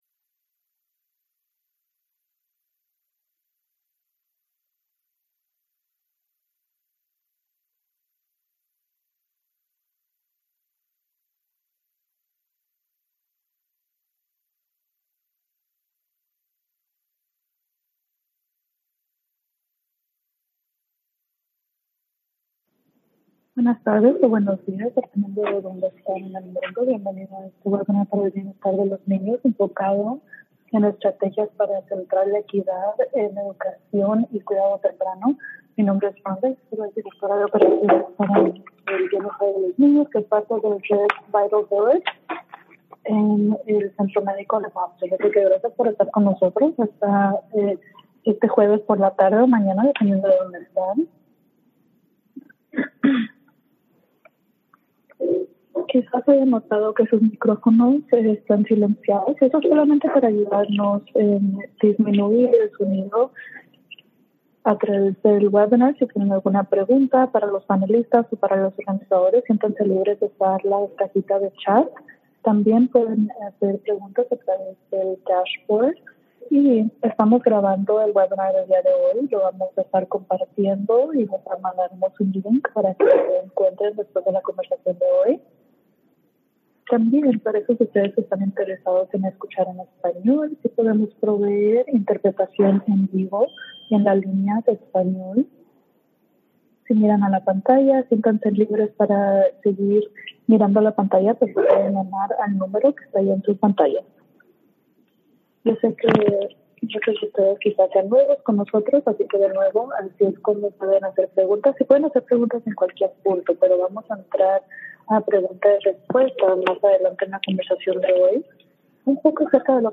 VIRTUAL LEARNING LAB ARCHIVE